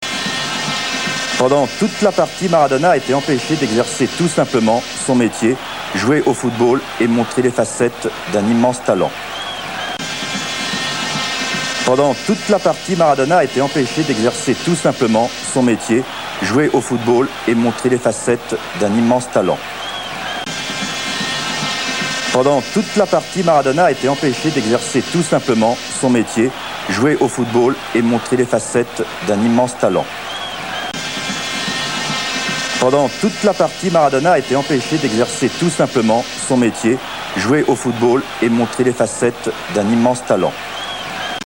[ Commentaires d'époque ]